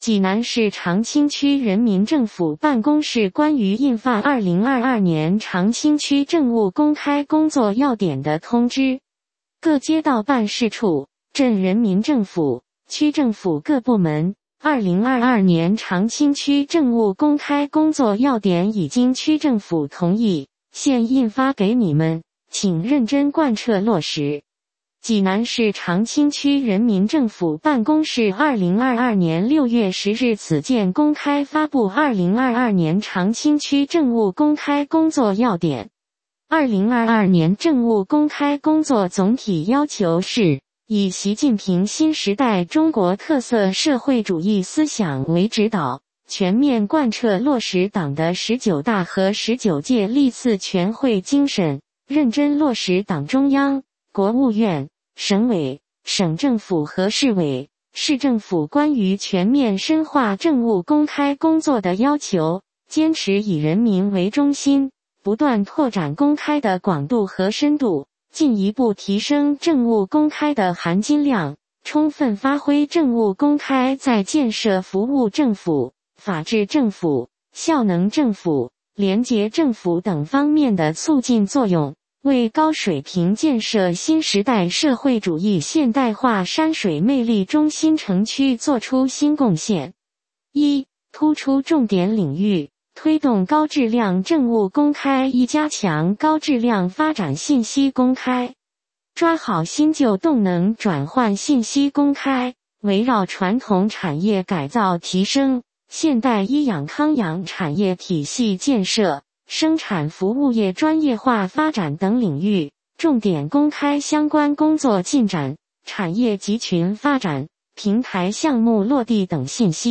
有声朗读：济南市长清区人民政府办公室关于印发2022年长清区政务公开工作要点的通知